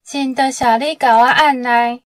ちなみに下記例文の場合はともに文中にあるため、“謝”7→3、“汝”2→1の通常の転調です。